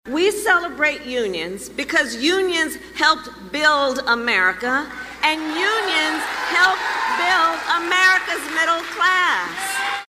AUDIO: Vice President Kamala Harris campaigns in Detroit
DETROIT, MI (WKZO AM/FM) – Vice President Kamala Harris spent part of her Labor Day campaigning in Detroit.
Harris spoke in the gym at Northwest High School.